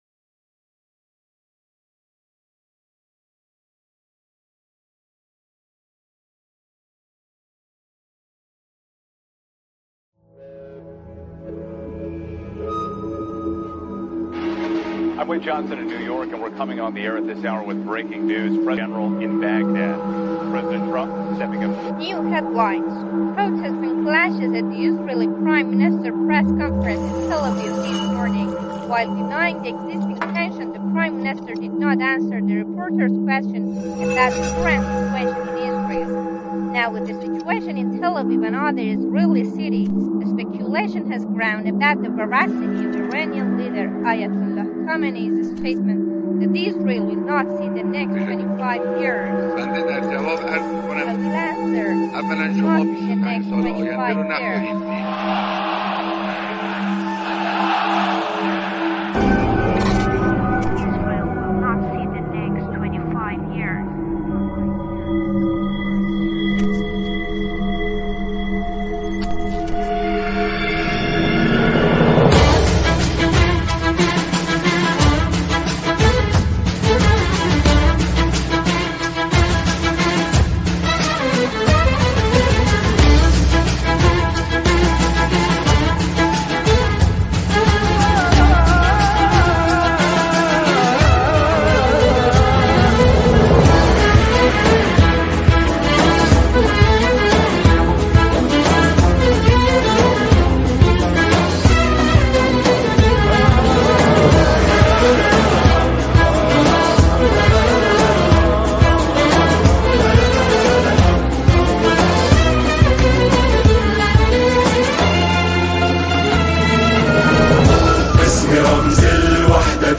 ارکستر زهی
نوازنده نی عربی
گروه کر لبنانی